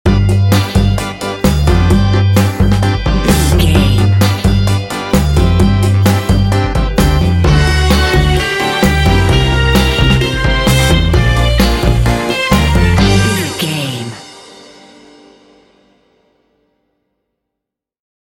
Uplifting
Aeolian/Minor
funky
smooth
groovy
driving
happy
drums
brass
electric guitar
bass guitar
organ
conga
Funk